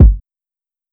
Kick (Use This Gospel).wav